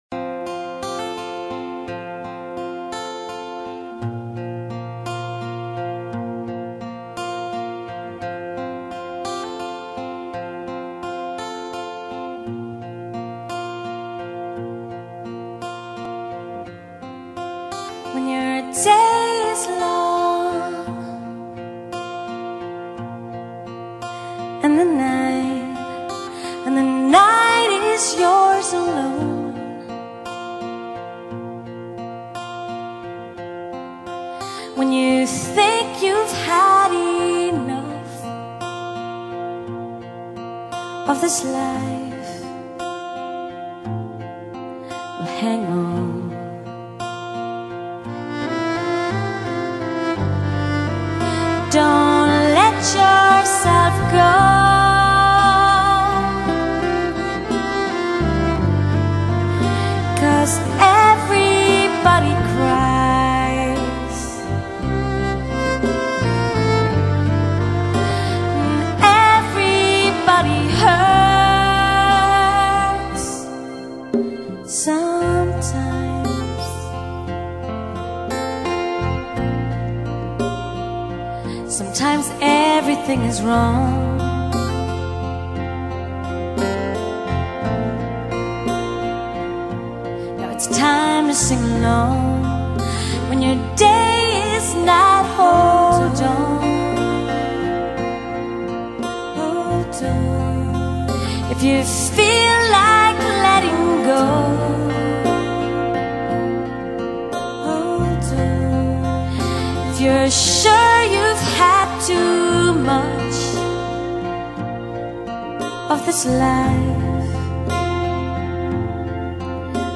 本唱片就是当时的现场收音。现场大量运用了管弦乐伴奏，整个演唱会几乎叫人找不到任何缺点。